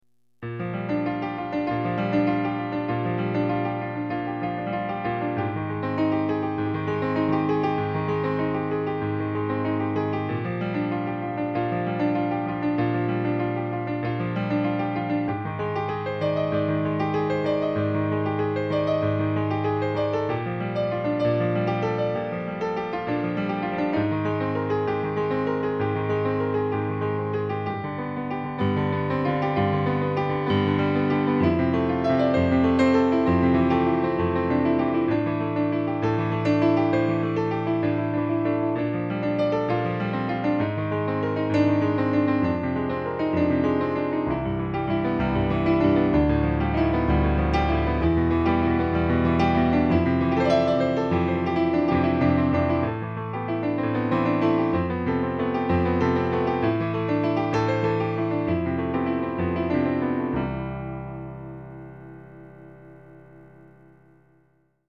These improvisations are just musical ideas and expressions all created in the moment, a stream of consciousness. The keyboard used is a controller so the musical response quality is limited to the nature of the animal.